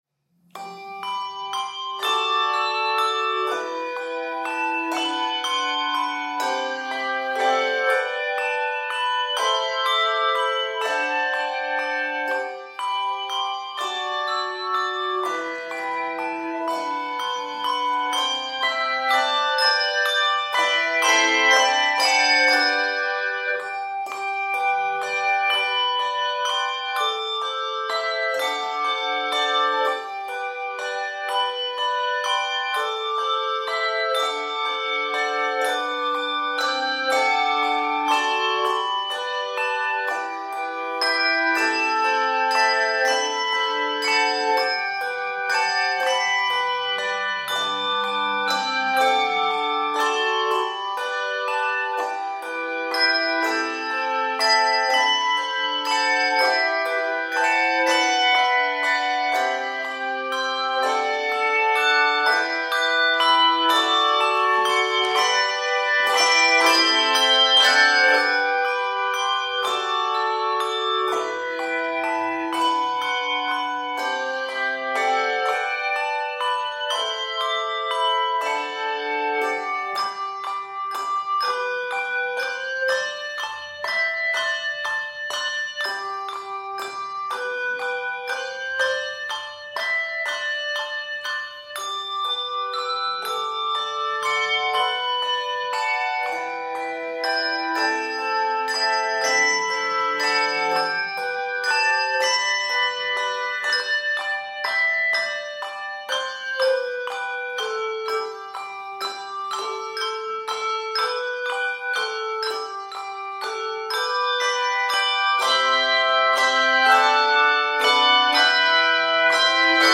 lively Christmas melody
Key of G Major.